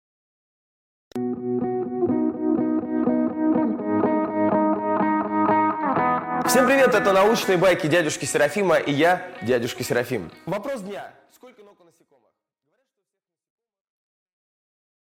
Аудиокнига Сколько ног у насекомых | Библиотека аудиокниг